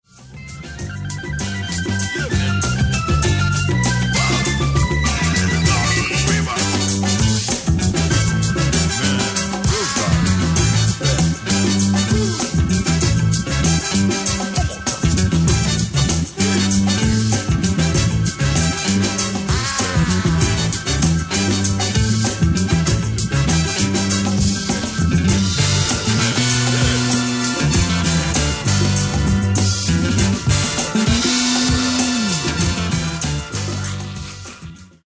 Genere:   Disco | Funky |